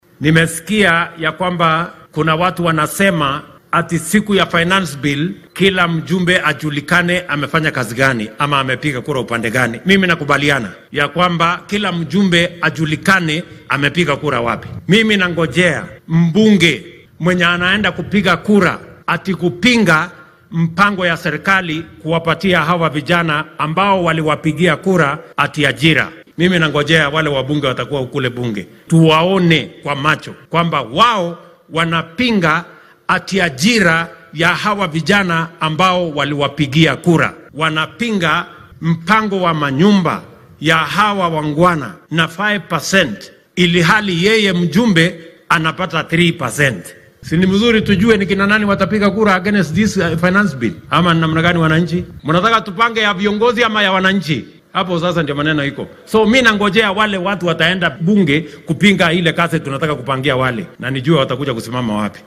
Madaxweynaha dalka William Ruto ayaa xilli uu ku sugnaa deegaanka Leshuta ee ismaamulka Narok sheegay inuu sugaya inuu arko xildhibaannada ka soo horjeedsan doono ansixinta hindise maaliyadeedka sanadkan ee Finance Bill 2023 marka toddobaadkan la hor geeyo baarlamaanka.